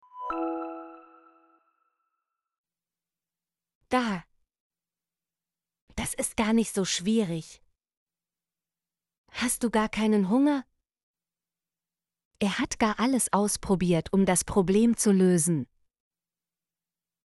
gar - Example Sentences & Pronunciation, German Frequency List